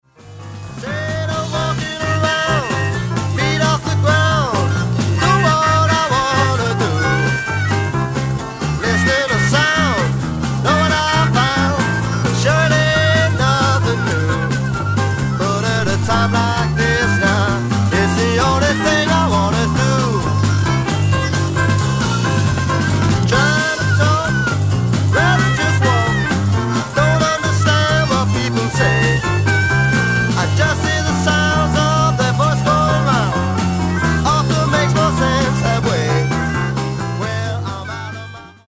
BLUES ROCK / COUNTRY BLUES / SSW